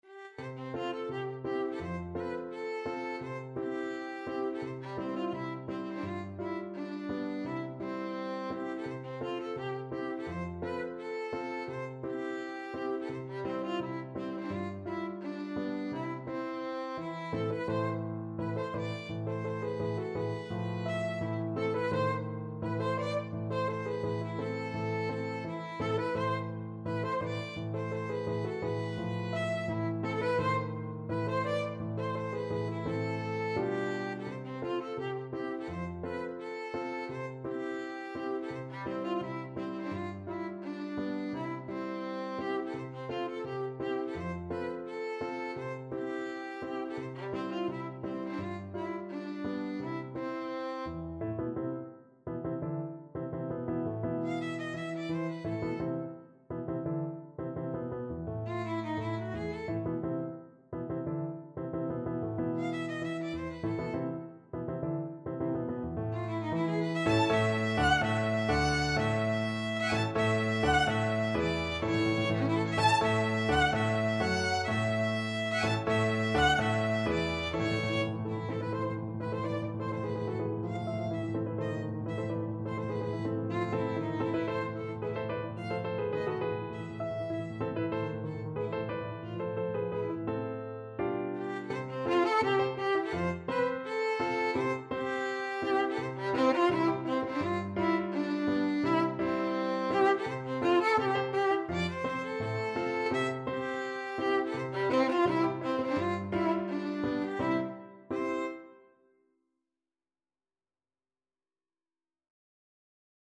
2/4 (View more 2/4 Music)
Allegretto = 85
Classical (View more Classical Violin Music)